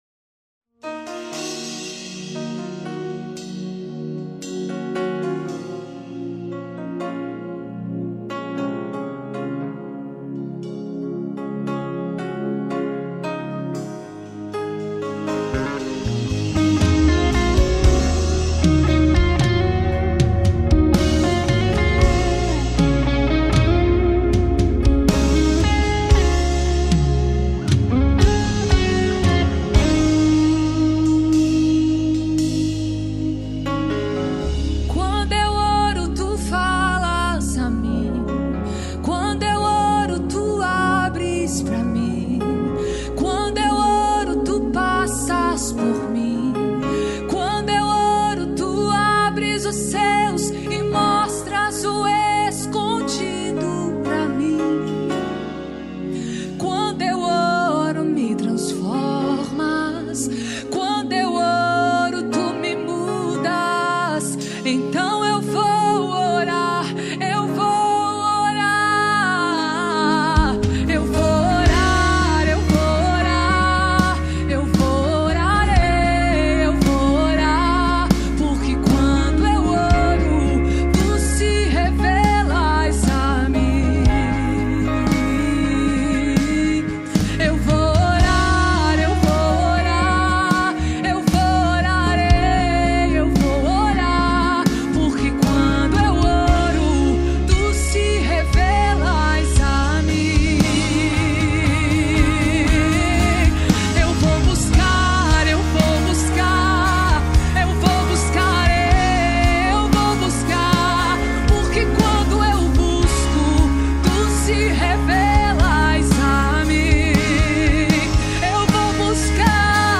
Gospel 2025